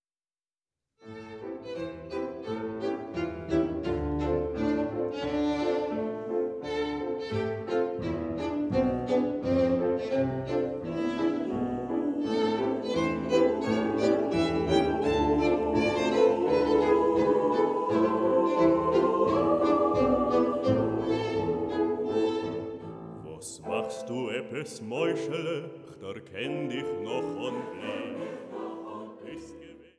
Sopran
Violine
Kontrabass
Klavier